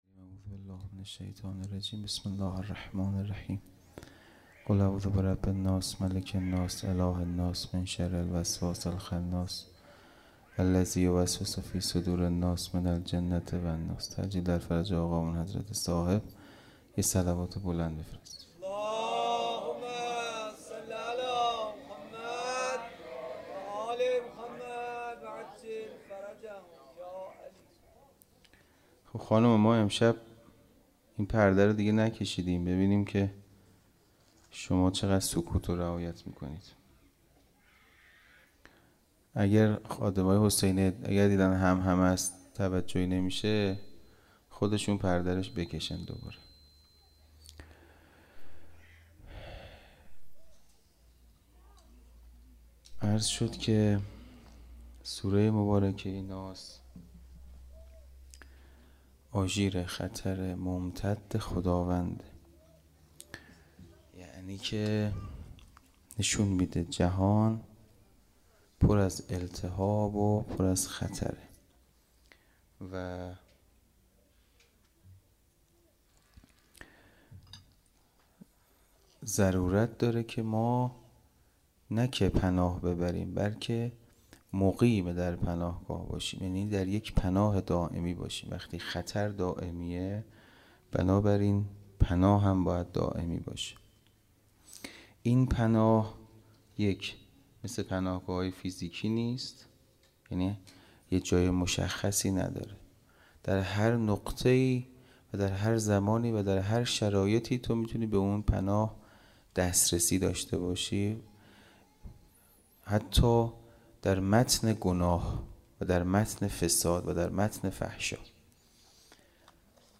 خیمه گاه - حسینیه کربلا - شب سوم محرم- سخنرانی